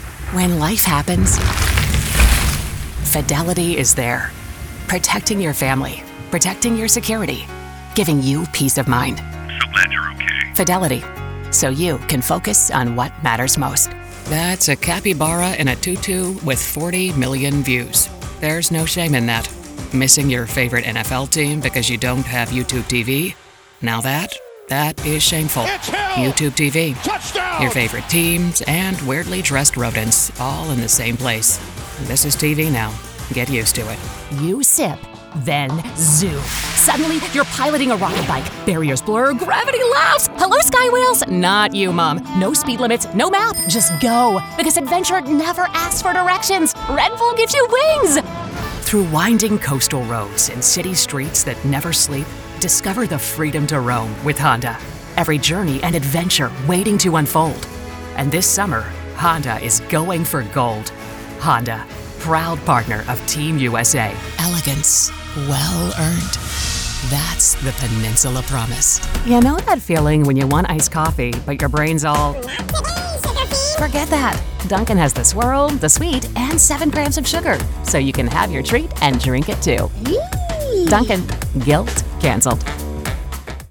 abc: articulate. believable. conversational.
Commercial.mp3